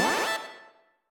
spawn_1.ogg